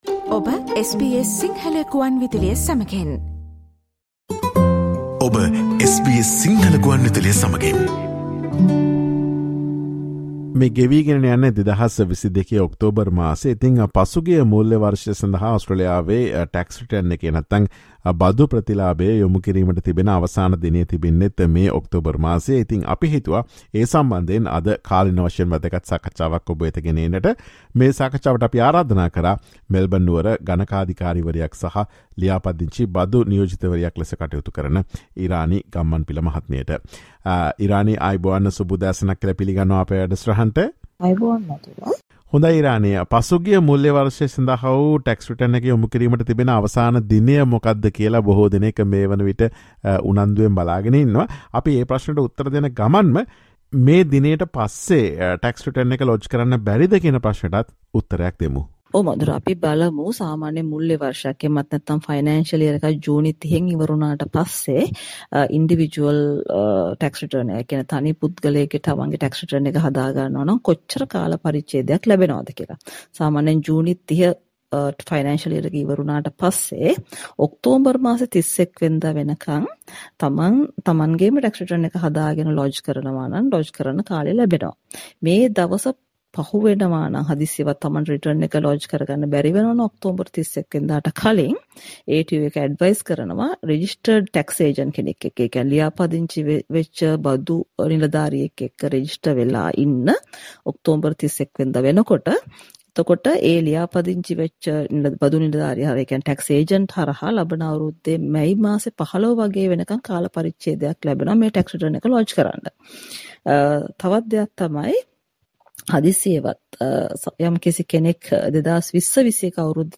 Listen to SBS Sinhala Radio's discussion on whether the ATO will be fined if the tax return is lodged after October 31 and how to get an extension.